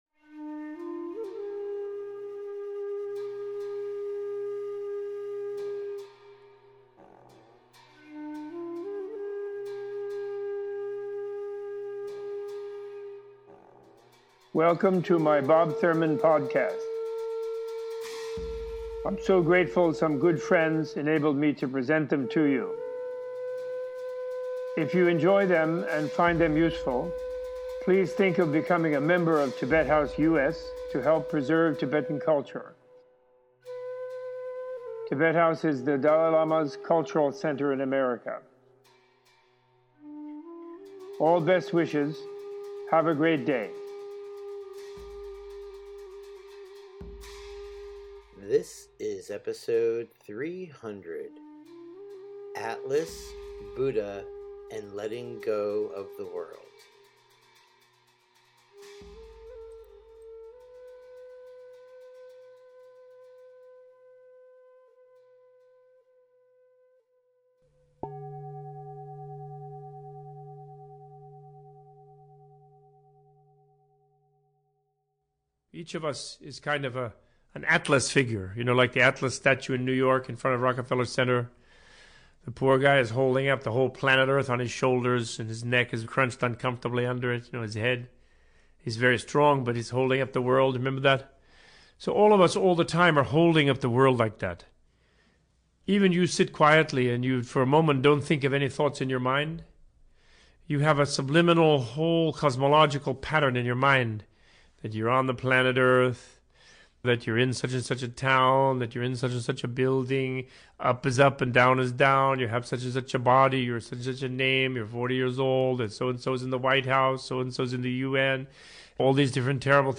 Opening with a meditation on the Greek myth of Atlas, the Titan condemned to hold up the earth, heavens and sky for eternity, Robert Thurman gives a teaching on the power of letting go and developing self compassion in meditation in order to change our relationship to our mind, our thoughts and to the world around us. This episode includes a short overview of the Buddhist perspective on spiritual evolution, instructions on developing the transcendent attitude as taught in the Tibetan Lam Rim tra